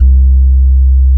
28SYN.BASS.wav